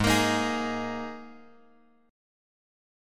G# Suspended 2nd
G#sus2 chord {4 x x 3 4 4} chord
Gsharp-Suspended 2nd-Gsharp-4,x,x,3,4,4.m4a